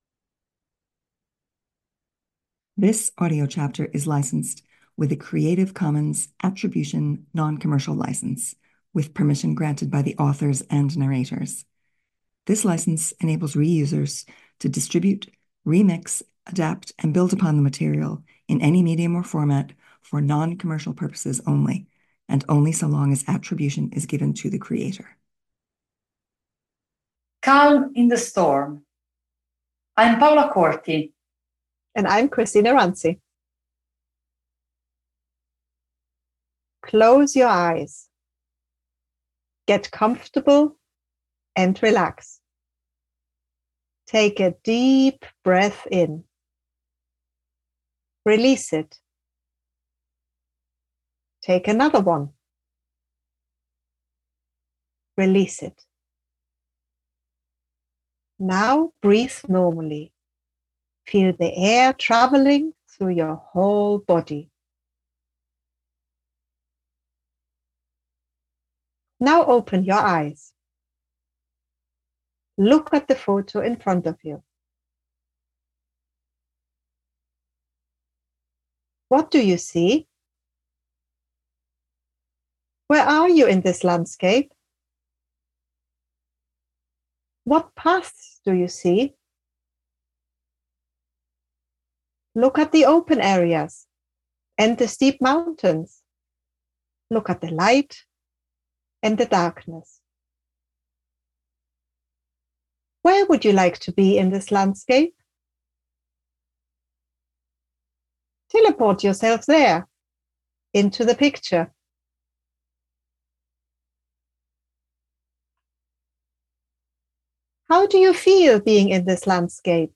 Through a body and mind experience, individuals are invited to close their eyes and immerse themselves in a relaxing activity that transports them into a mountainous landscape and ecosystem.